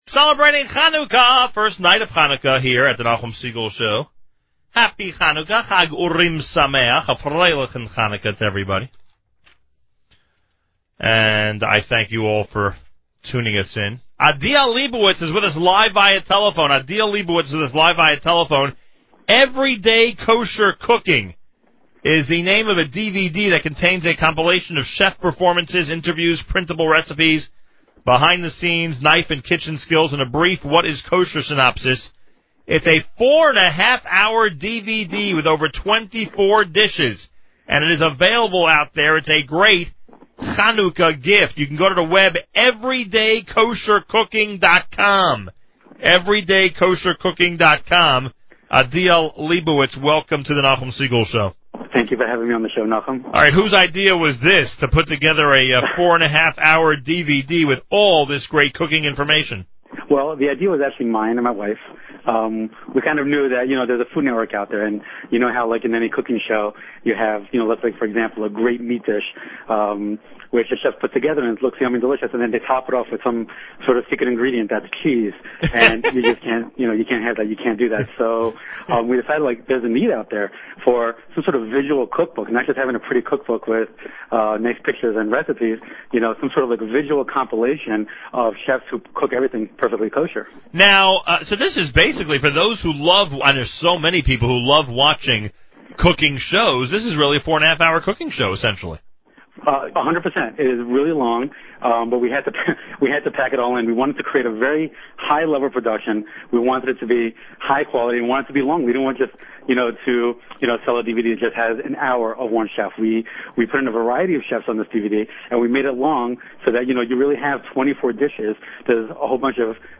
interview.mp3